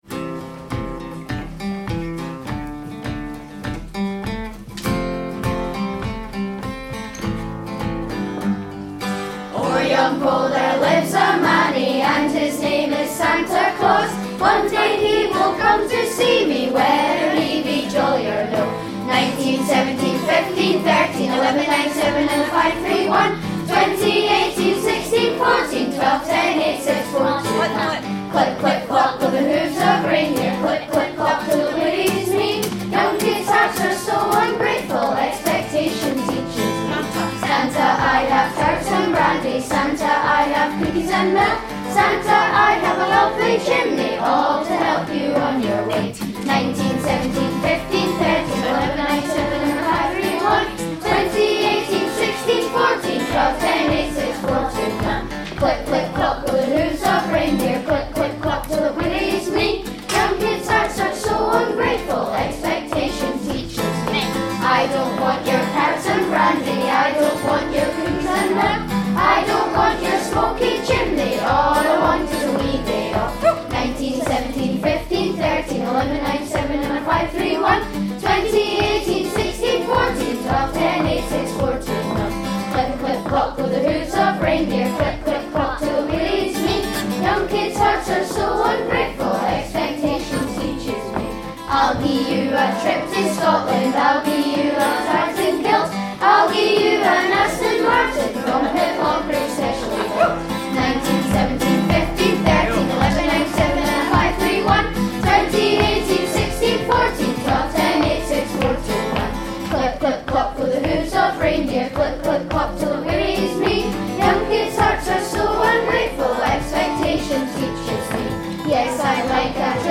They have written a Christmas themed song using Scots language.